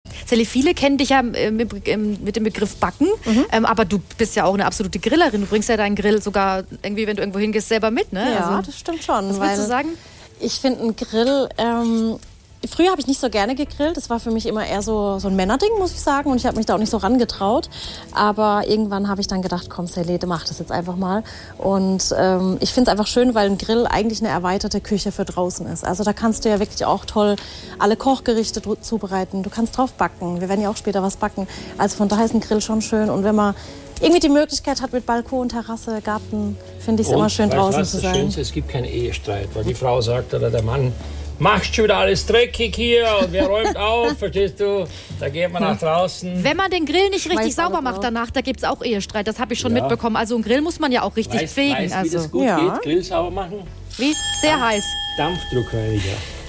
Beim großen SWR3 Grillen 2025 reden Sally und Johann Lafer darüber, wieso Grillen Ehestreit verhindern kann.